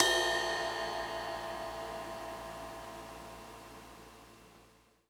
Closed Hats
Ride_3.wav